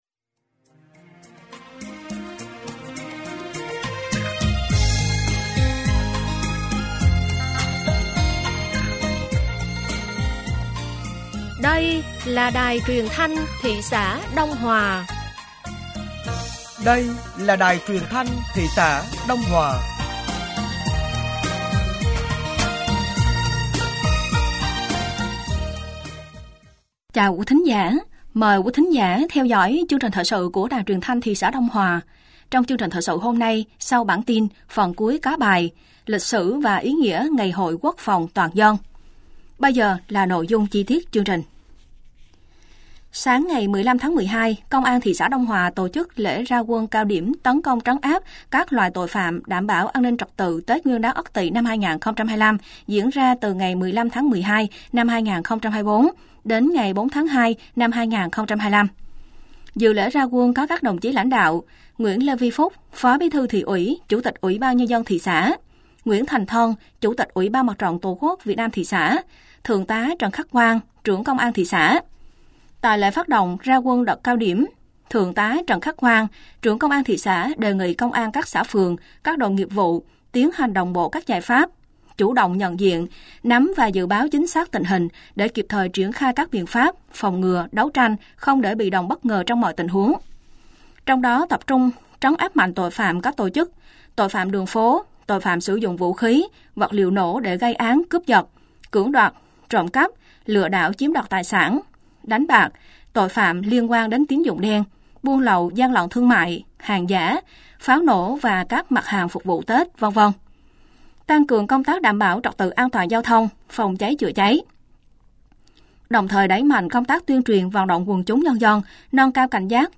Thời sự tối ngày 16 và sáng ngày 17 tháng 12 năm 2024